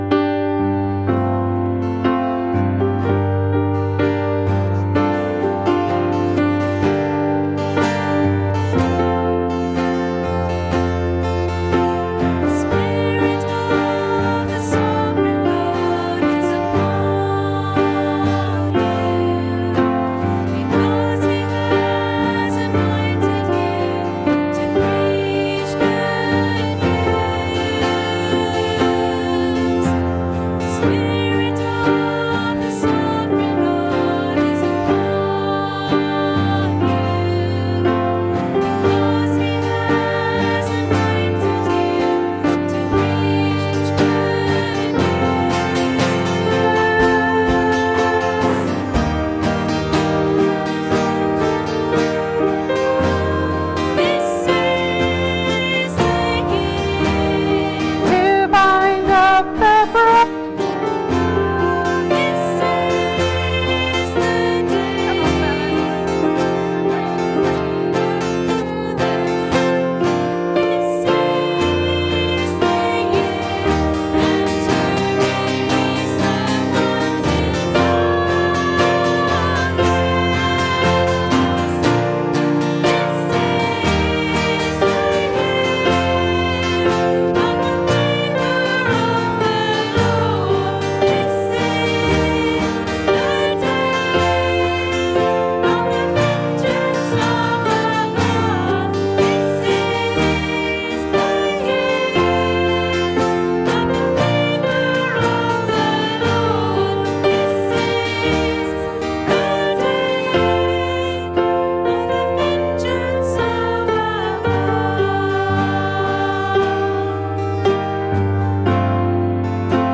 Join us this Father's Day for another in our sermon series 'We've got work to do!'
Service Audio